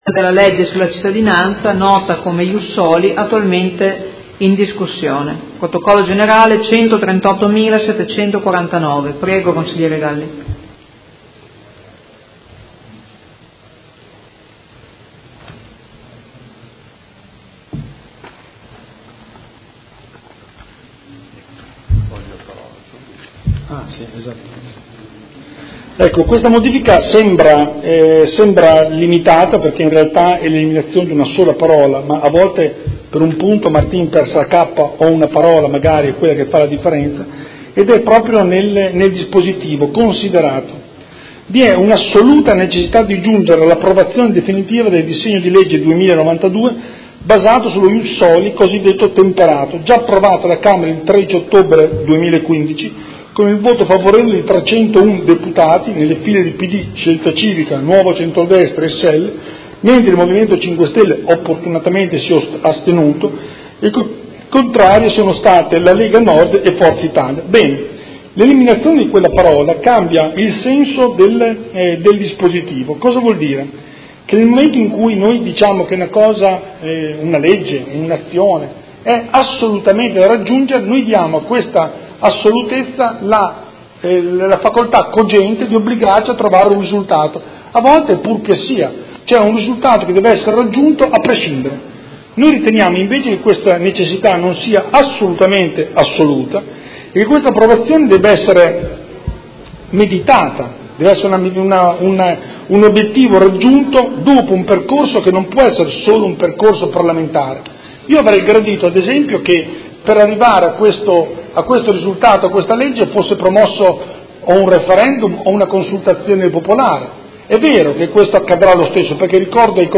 Seduta del 14/09/2017 Presenta emendamento 138749